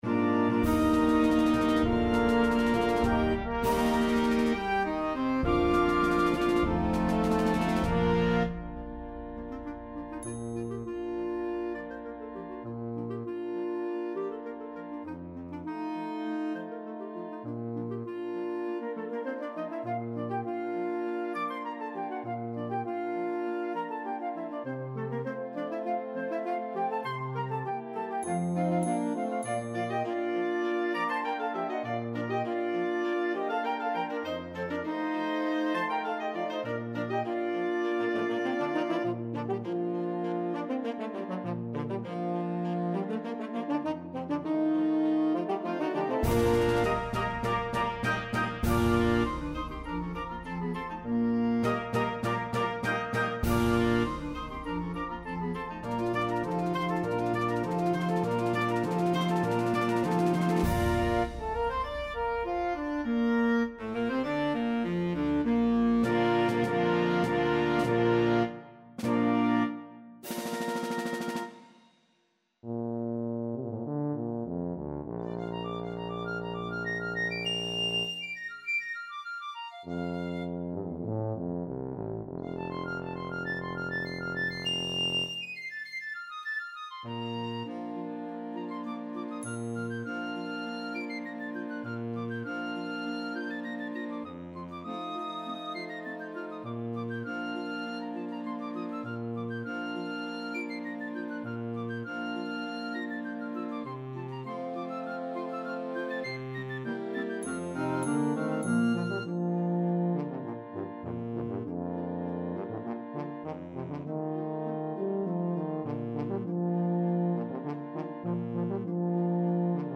Ein Duett für Piccolo und Tuba!